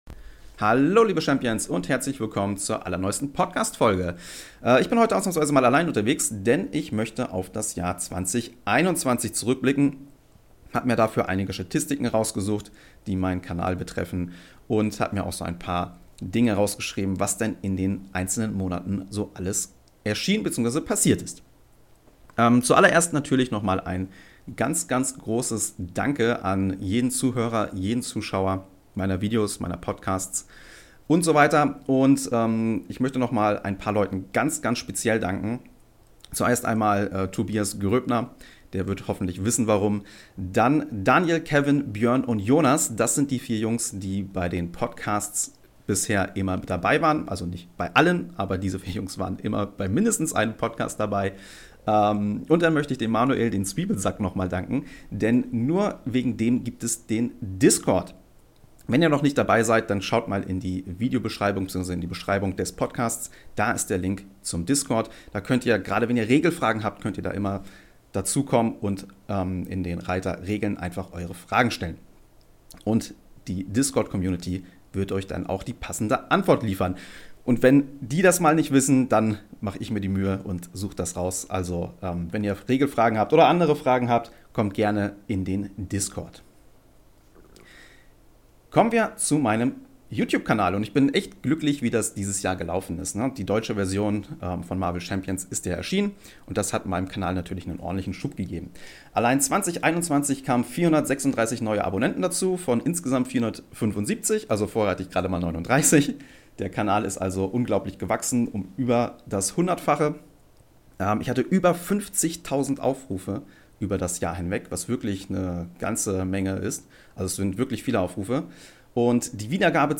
Daher lade ich jedes mal bis zu drei meiner Zuschauer ein um mich über verschiedene Themen zu unterhalten.